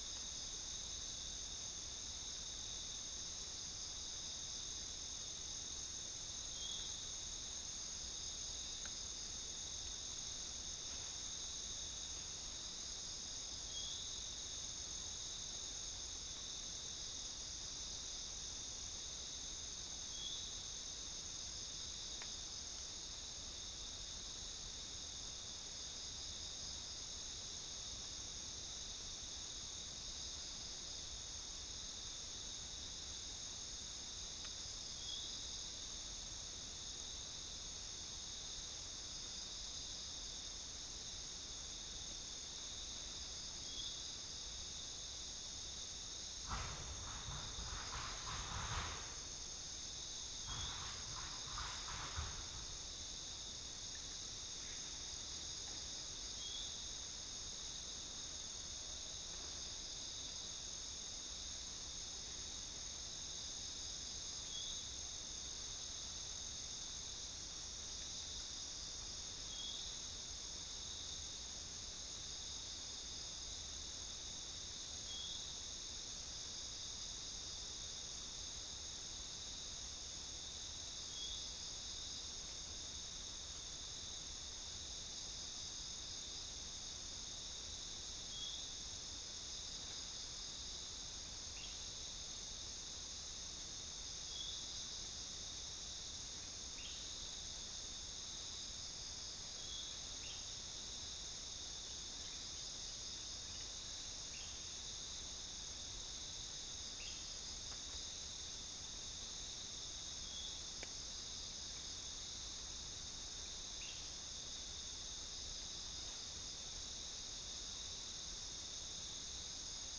Chalcophaps indica
Gallus gallus
Psilopogon duvaucelii
Halcyon smyrnensis
Prinia familiaris
Orthotomus sericeus